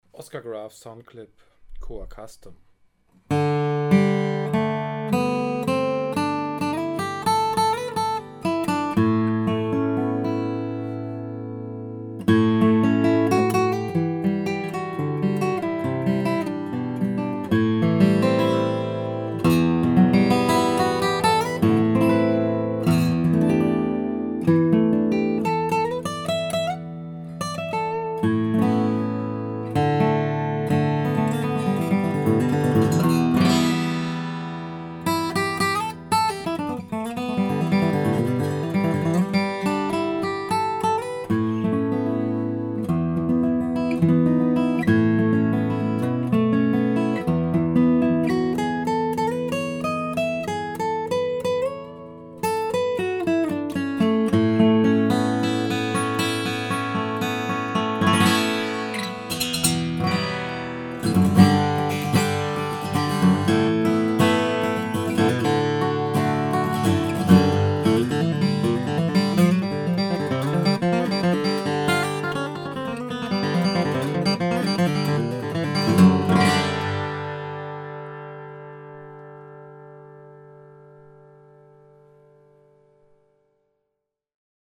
Extrem ausgeglichener und nuancenreicher Ton.
Flatpicked
Boden und Zargen: sehr stark geflammtes Koa
Decke: Tiroler Fichte